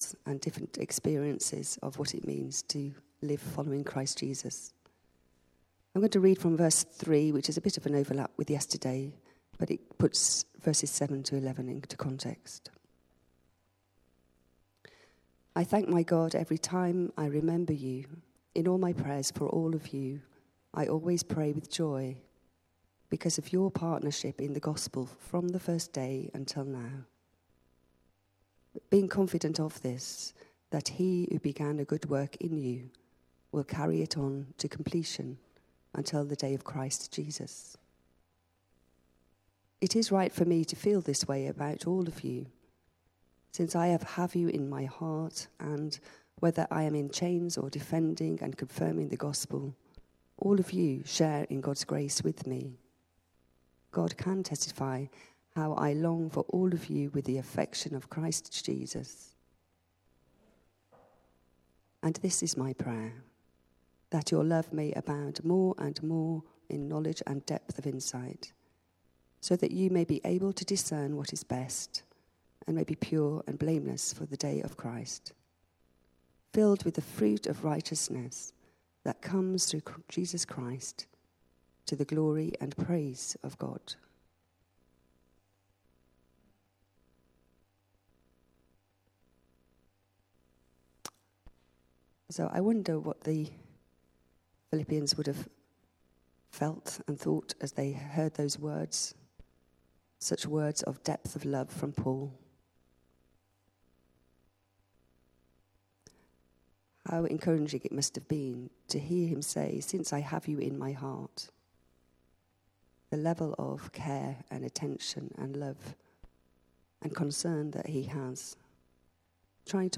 Morning Prayer